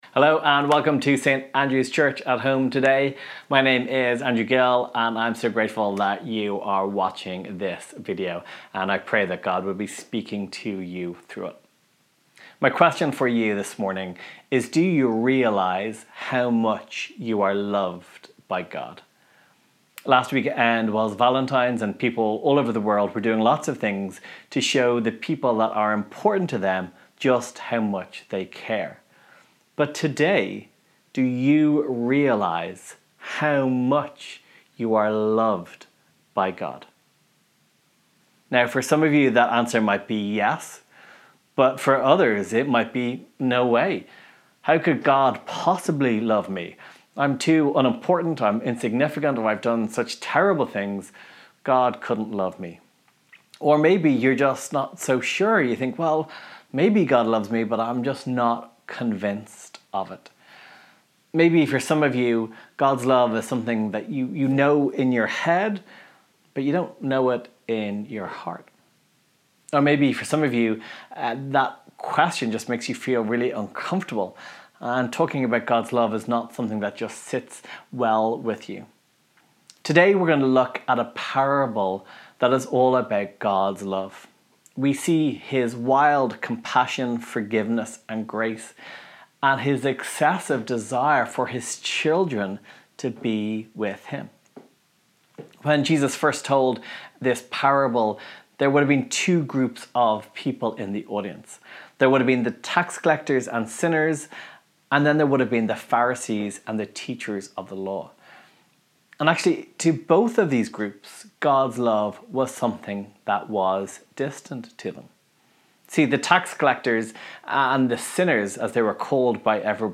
Today we continue our sermon series looking at the Parables of Jesus.